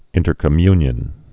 (ĭntər-kə-mynyən)